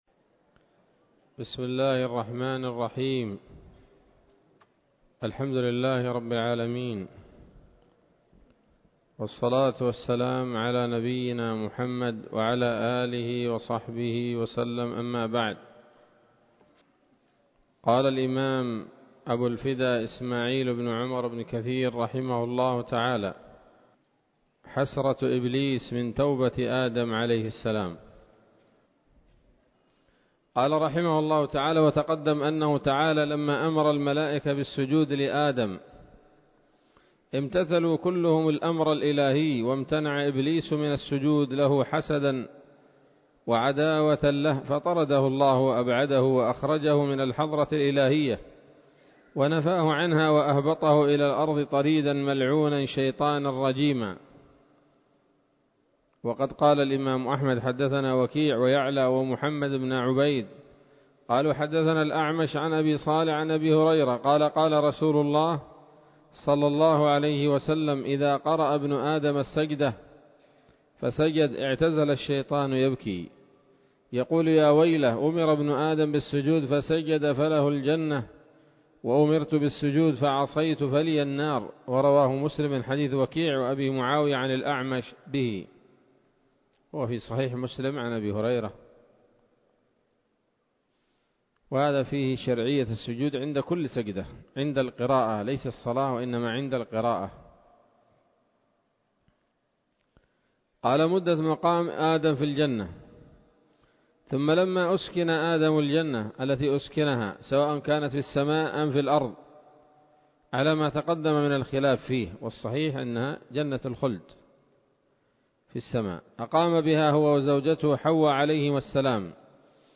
الدرس الرابع عشر من قصص الأنبياء لابن كثير رحمه الله تعالى